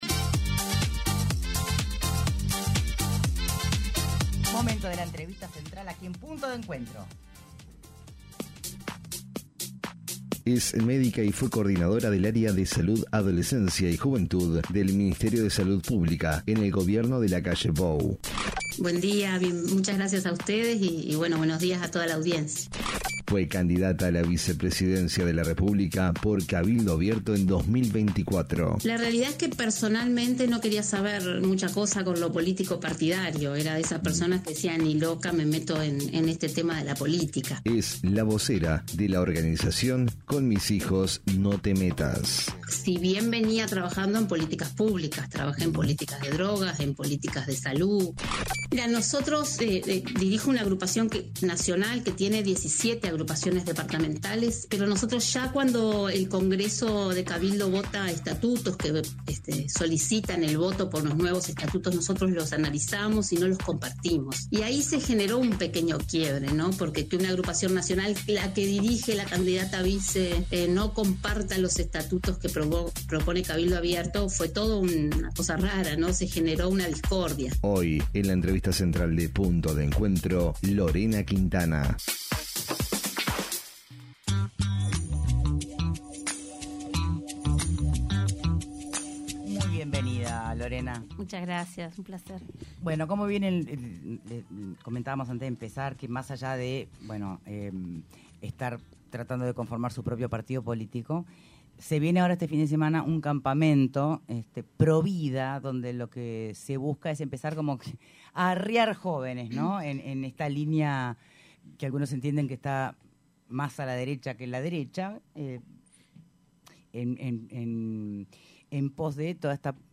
AUDIO La excandidata a la vicepresidencia por Cabildo Abierto, Lorena Quintana habló en Punto de Encuentro del movimiento político que planea formar con valores cristianos, rechazó la llamada agenda de derechos, el aborto (incluso en casos de violación) y cuestionó la alianza de su expartido con el Frente Amplio en algunas votaciones en el actual […]